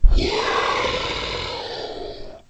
龙的声音 " 龙的咆哮 轻度7
描述：为制作史瑞克而制作的龙声。使用Audacity录制并扭曲了扮演龙的女演员的声音。
Tag: 生物 发声 怪物